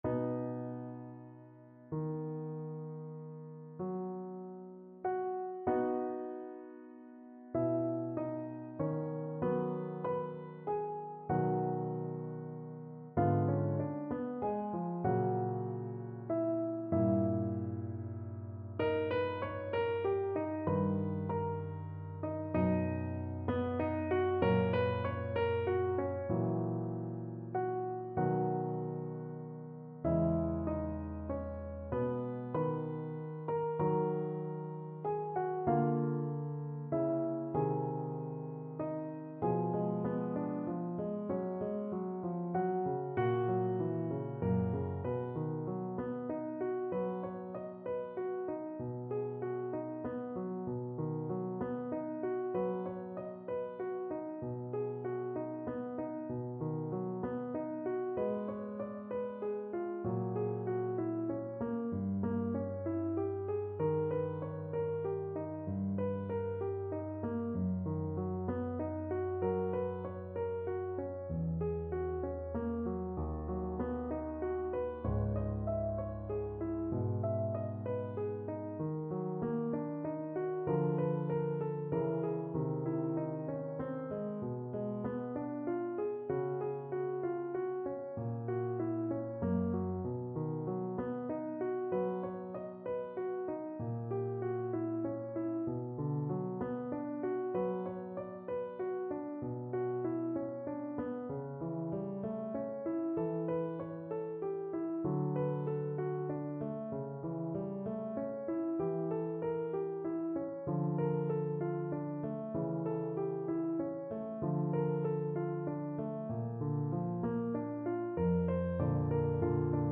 Adagio un poco lento e dolce assai (=96)
9/8 (View more 9/8 Music)
Classical (View more Classical Voice Music)